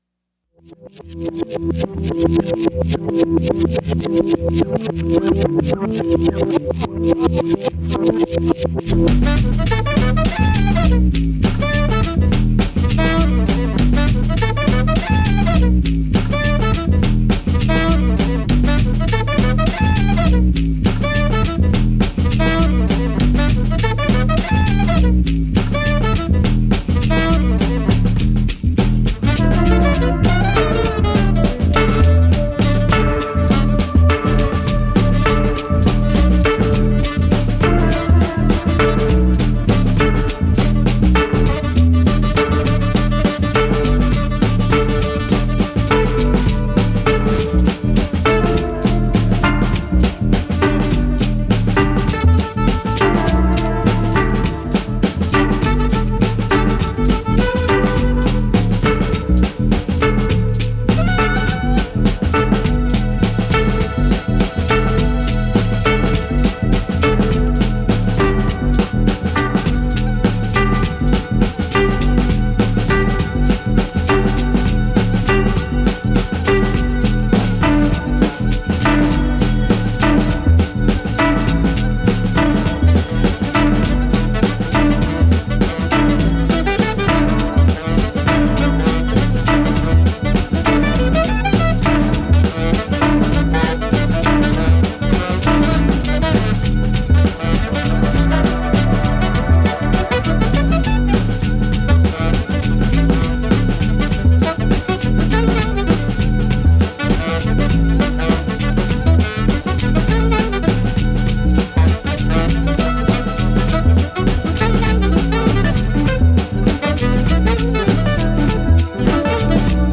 basse
synthétiseurs, live-sampling
saxophone alto, effets
batterie, percussions
guitare électrique, effets